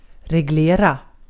Ääntäminen
IPA : /ˈsɛtəl/ US : IPA : [ˈsɛtəl]